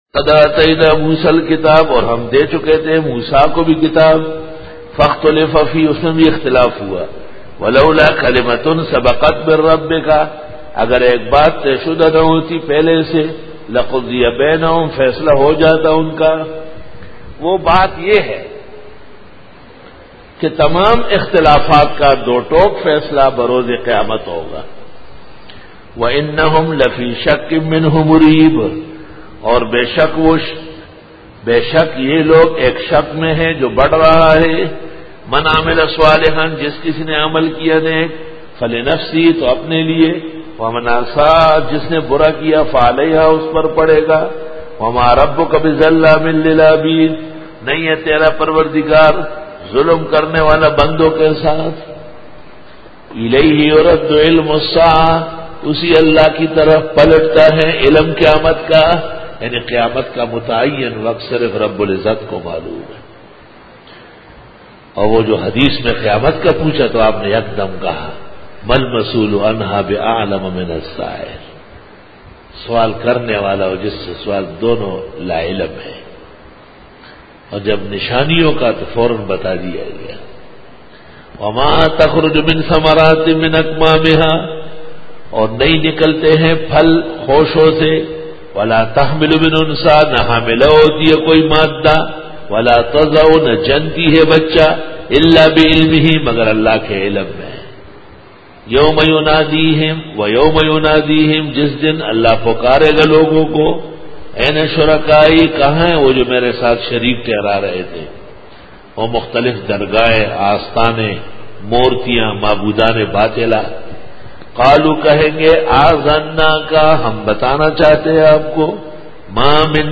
Dora-e-Tafseer 2012